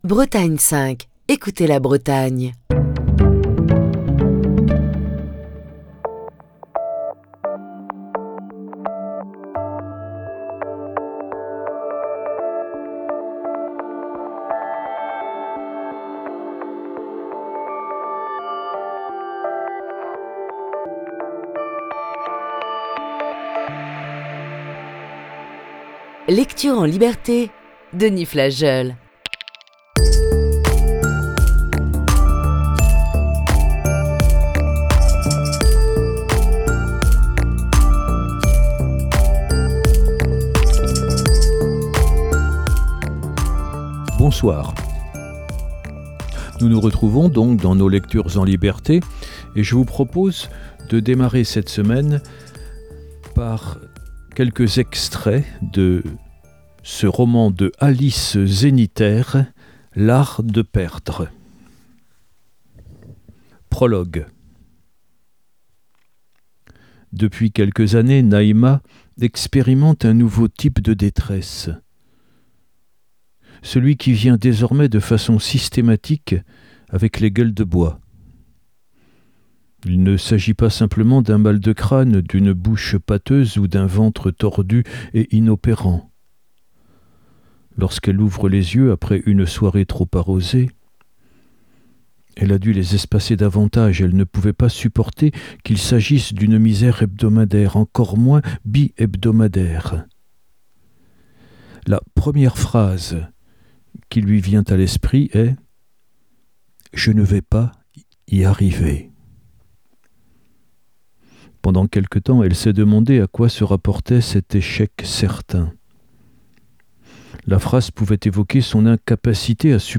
Voici ce lundi, la première partie de ce récit.